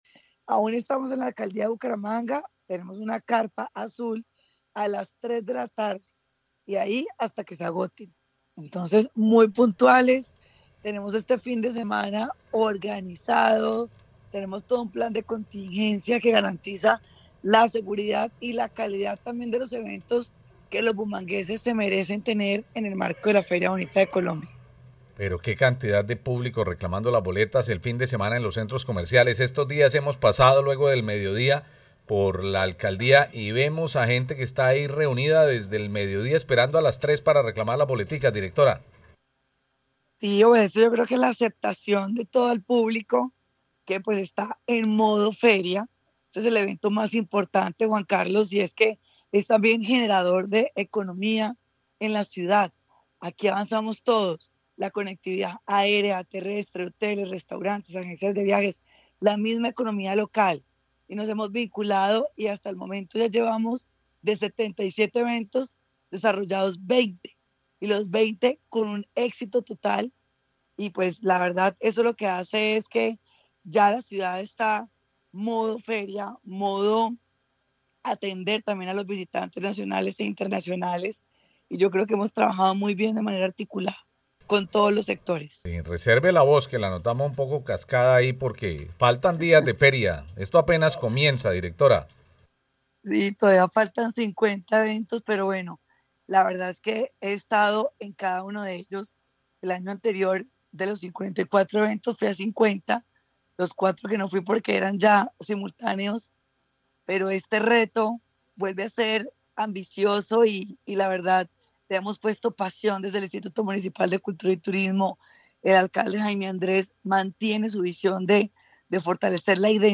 Laura Patiño, directora de Cultura y Turismo de Bucaramanga